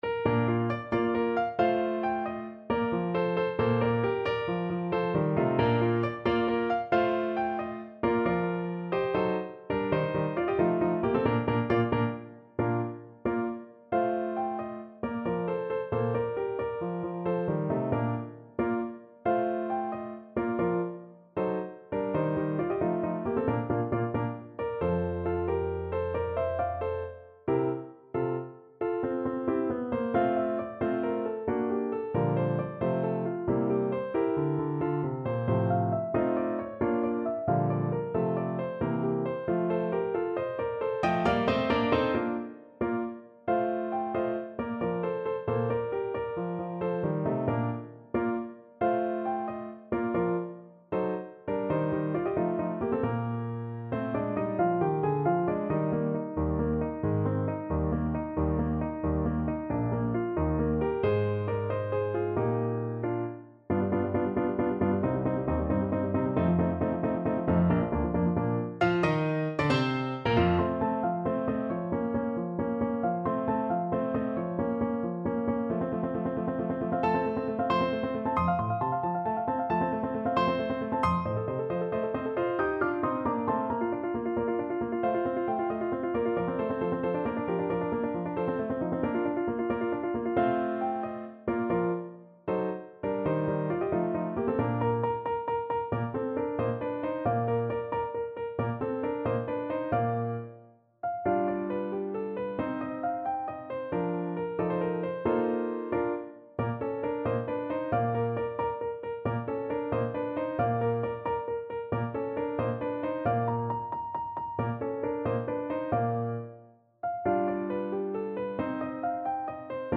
6/8 (View more 6/8 Music)
. = 90 Allegretto vivace
Classical (View more Classical French Horn Music)